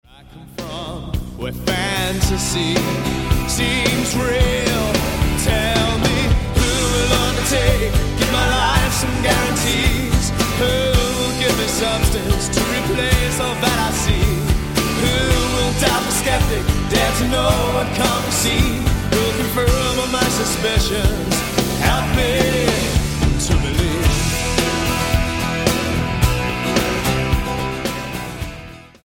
Rock Album
Style: Rock